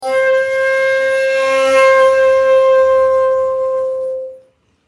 SONS ET LOOPS DE SHAKUHACHIS GRATUITS
Shakuhachi 46